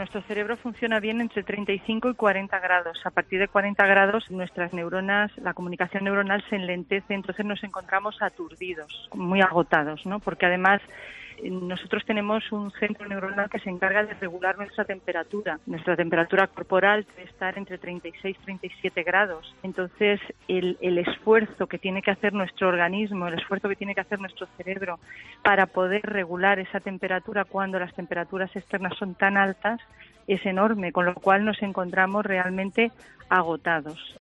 Nuestros compañeros de 'Mediodía COPE' y al hilo de este tema, hablaron con un experto respecto a esta cuestión. En concreto, respondieron a la siguiente pregunta: ¿Cómo afecta a tu cerebro la ola de calor?